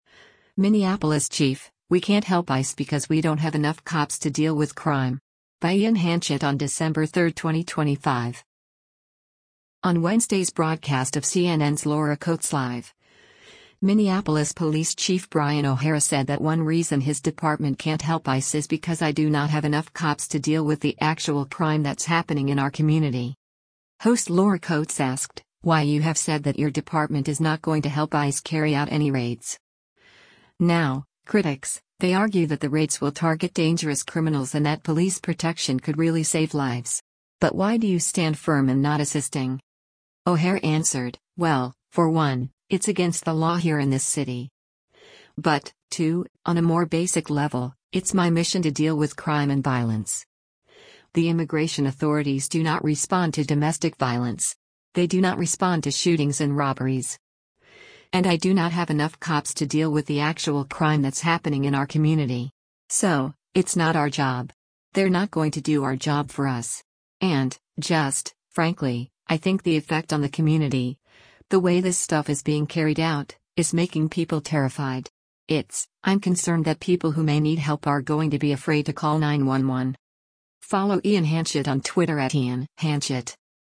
On Wednesday’s broadcast of CNN’s “Laura Coates Live,” Minneapolis Police Chief Brian O’Hara said that one reason his department can’t help ICE is because “I do not have enough cops to deal with the actual crime that’s happening in our community.”